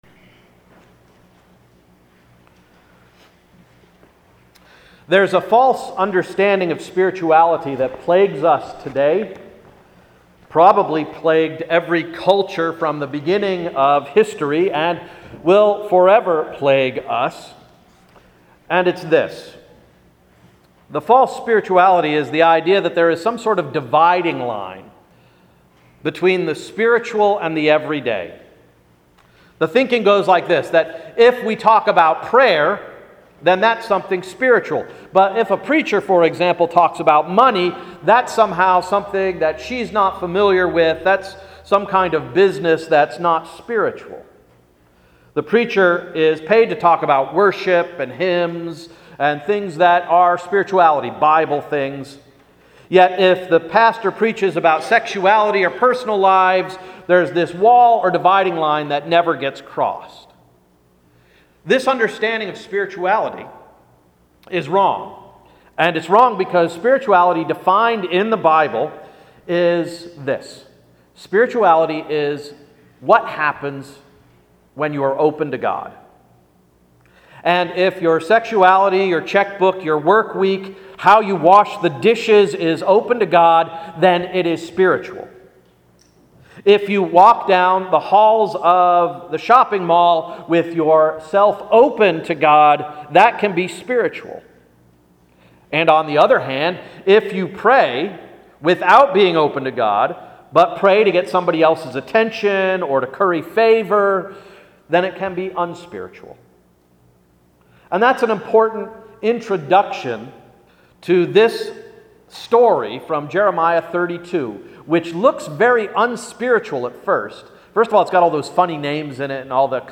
Sermon of September 29–“Surprising Investments”